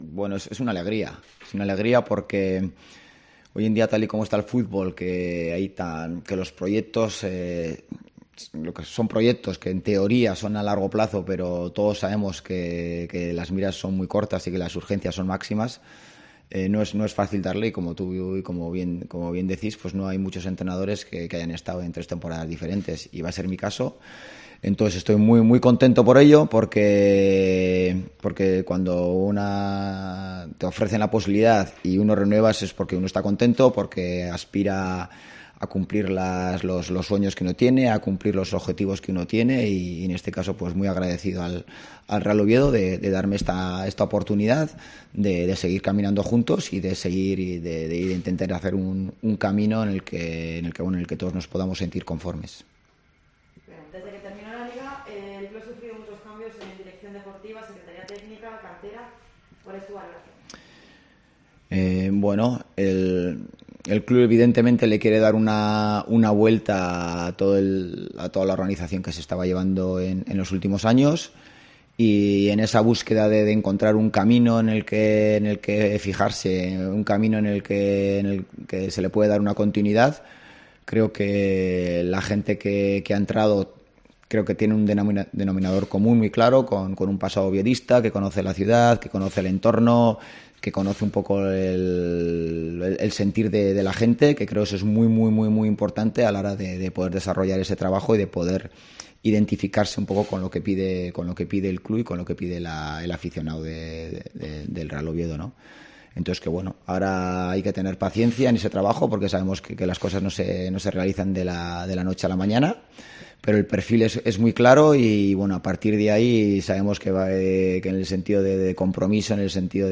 Rueda de prensa de Ziganda